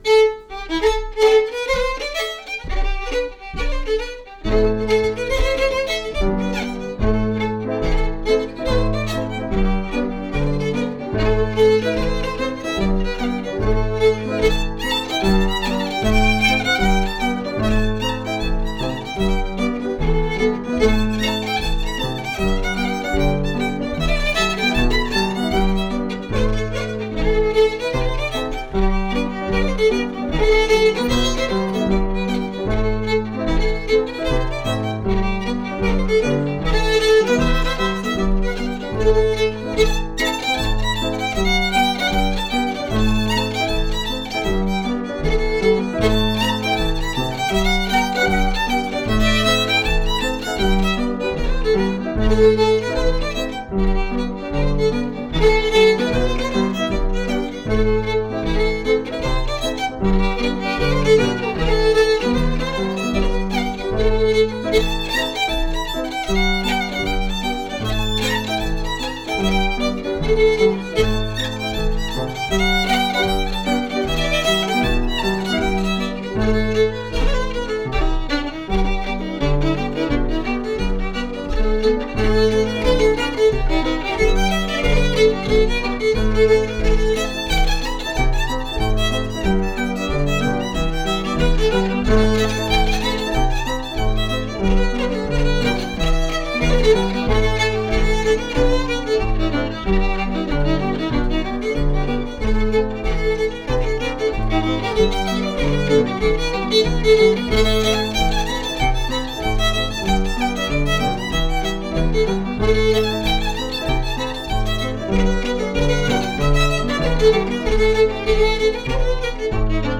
pno